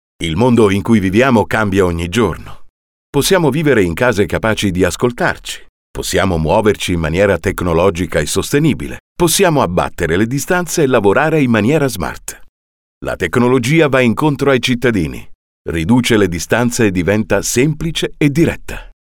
A sensual, captivating, deep, baritoneal, reassuring, incisive voice.
Sprechprobe: eLearning (Muttersprache):